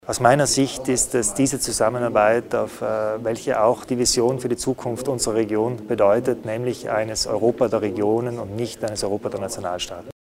Landeshauptmann Günther Platter zur Zusammenarbeit zwischen Tirol und Südtirol